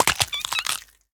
Minecraft Version Minecraft Version snapshot Latest Release | Latest Snapshot snapshot / assets / minecraft / sounds / mob / turtle / baby / egg_hatched1.ogg Compare With Compare With Latest Release | Latest Snapshot
egg_hatched1.ogg